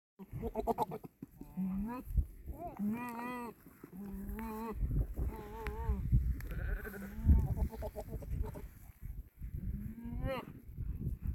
Goats are so Mp3 Sound Effect Sound on!! Goats are so strange!